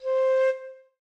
flute_c.ogg